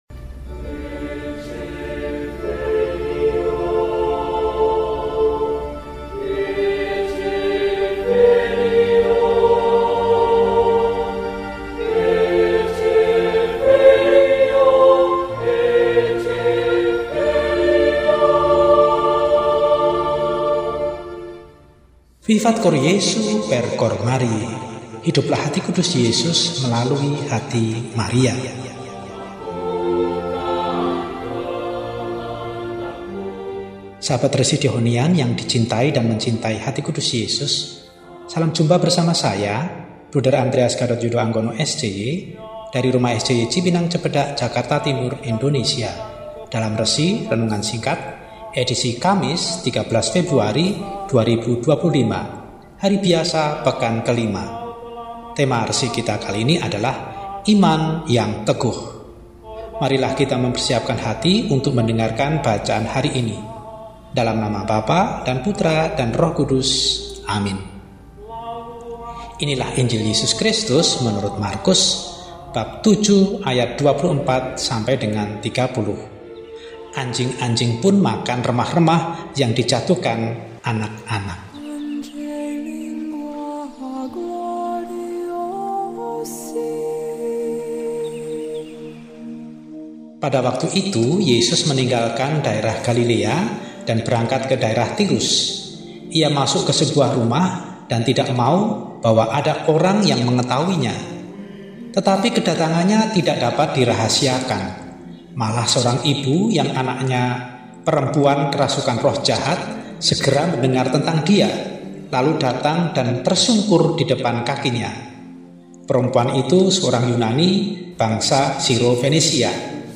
Kamis, 13 Februari 2025 – Hari Biasa Pekan V – RESI (Renungan Singkat) DEHONIAN